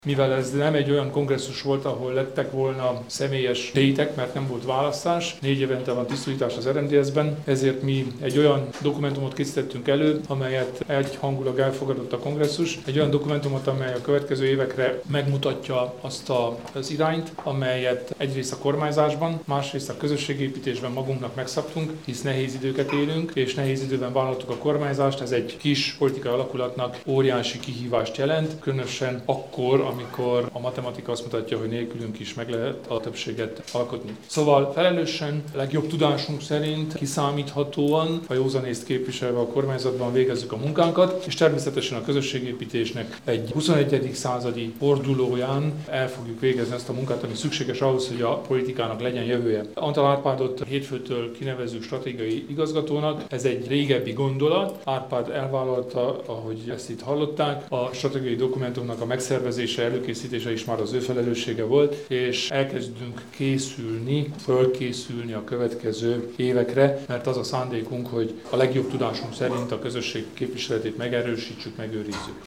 Ezekről számolt be Kelemen Hunor, az RMDSZ elnöke, a kongresszus utáni sajtótájékoztatóján: